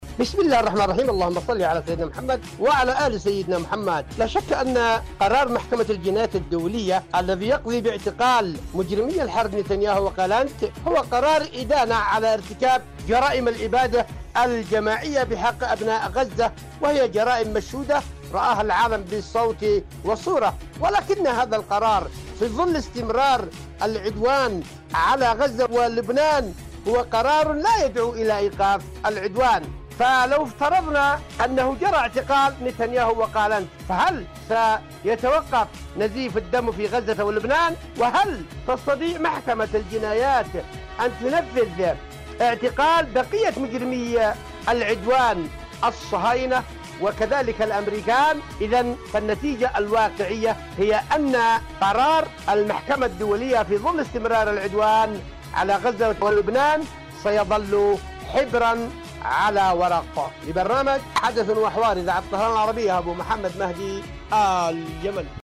إذاعة طهران- حدث وحوار